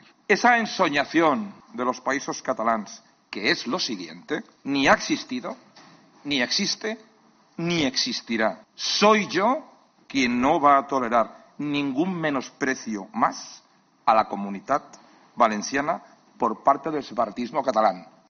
Durante su discurso en la sesión de la Comisión General de Comunidades Autónomas del Senado, Mazón ha defendido que "la pluralidad no es patrimonio exclusivo de nadie"